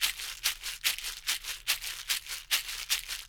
BOL SHAKER.wav